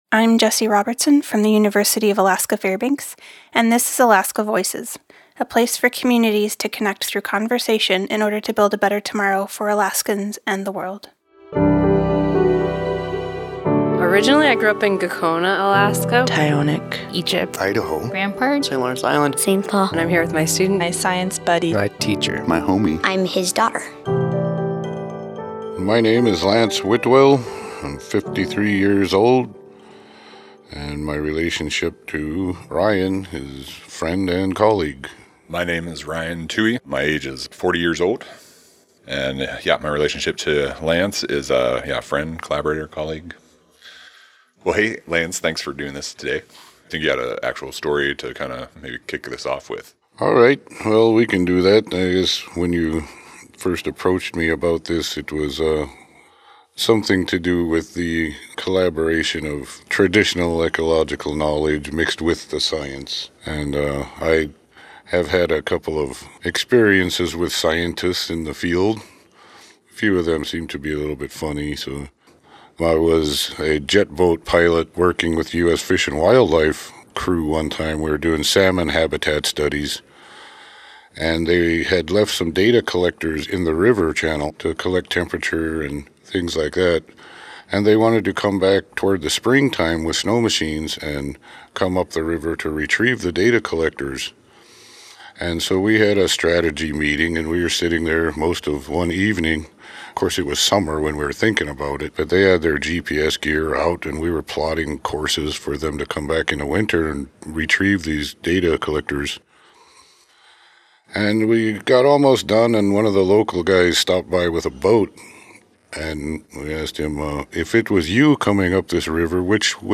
This interview was recorded in collaboration with StoryCorps.